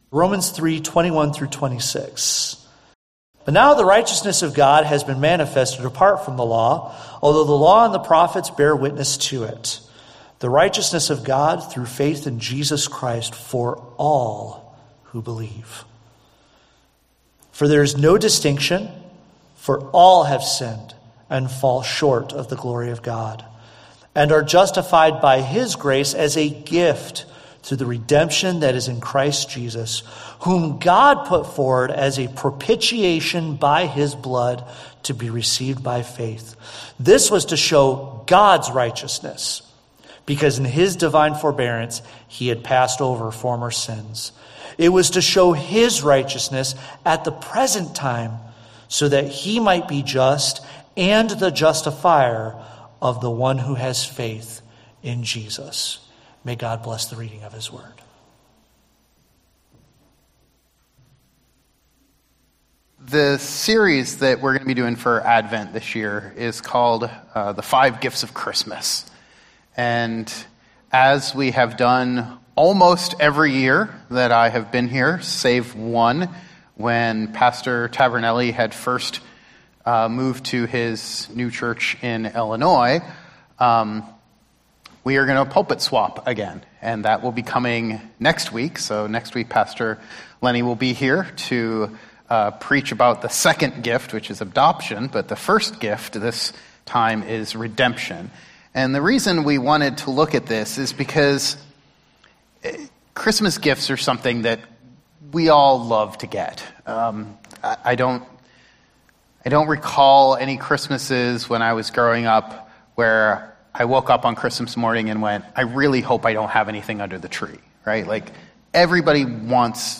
Download MP3 – Sermon Discussion Questions